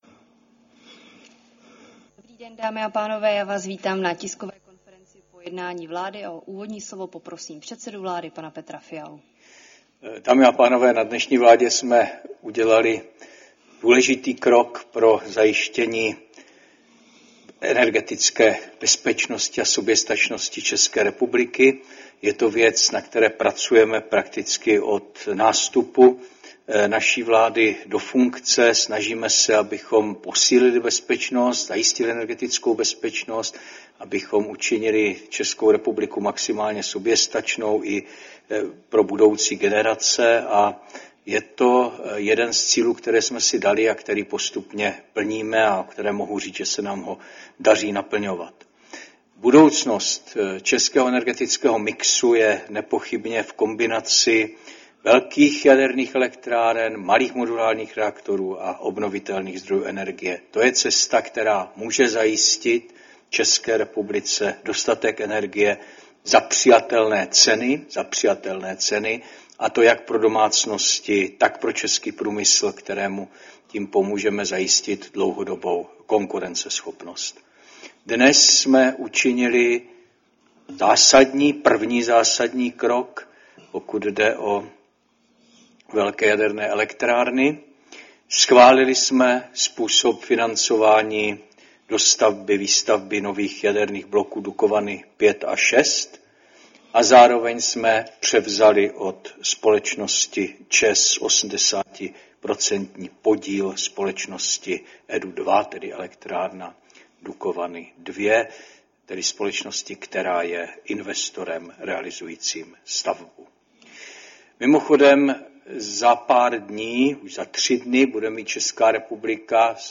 Tisková konference po jednání vlády, 30. dubna 2025